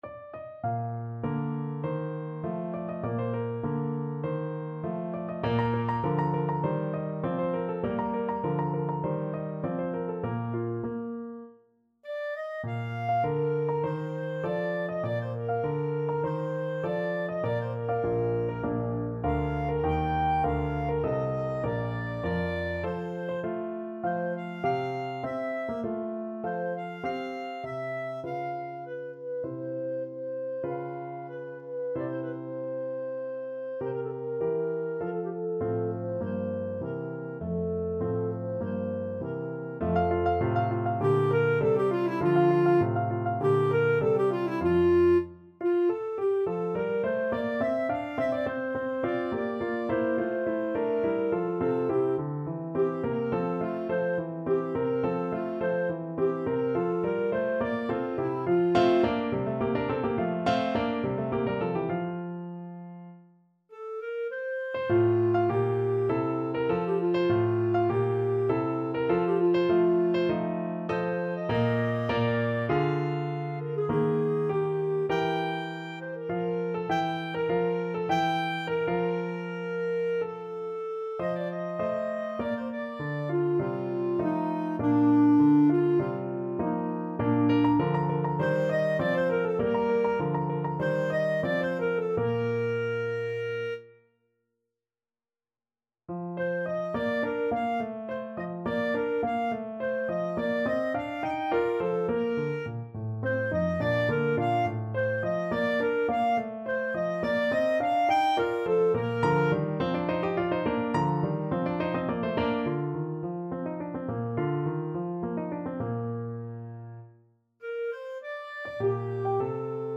2/4 (View more 2/4 Music)
~ = 50 Larghetto
Classical (View more Classical Clarinet Music)